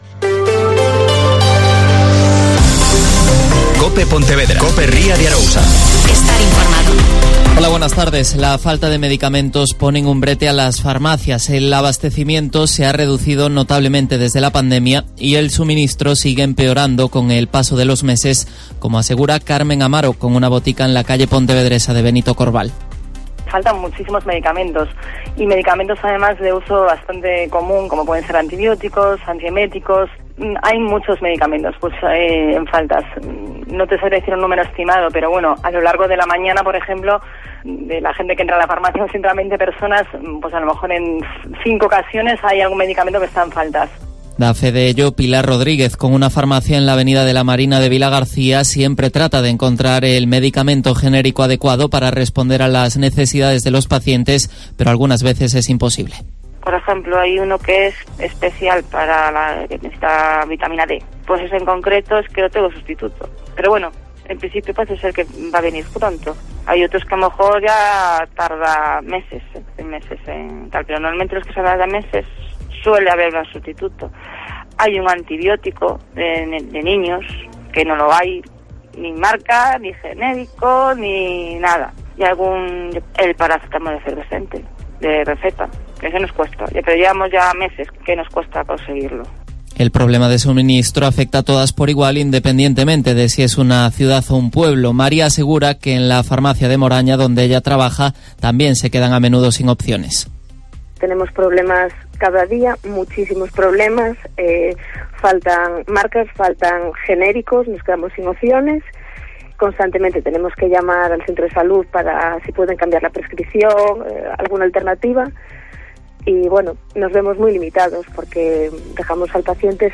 Mediodía COPE Ría de Arosa (Informativo 14:20h)